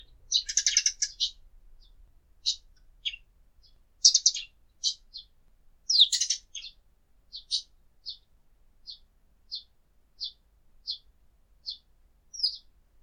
朝のスズメ
sparrows.mp3